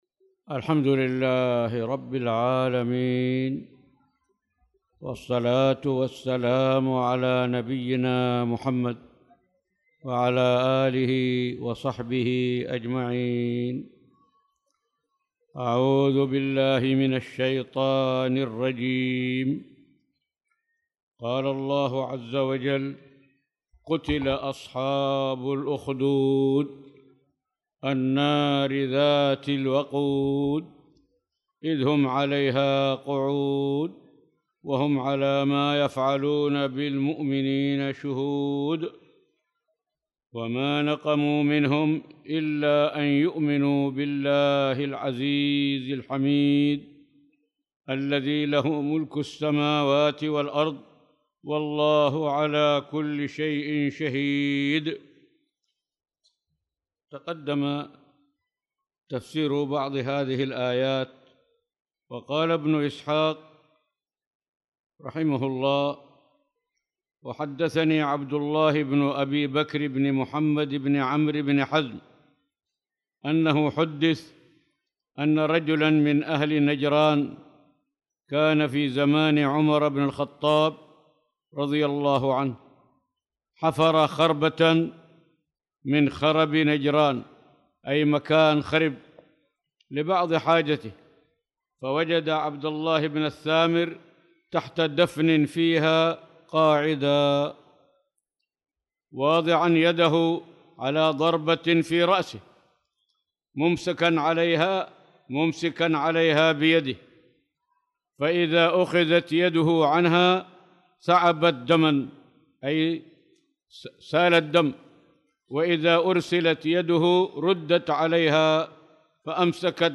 تاريخ النشر ٢٠ شعبان ١٤٣٧ هـ المكان: المسجد الحرام الشيخ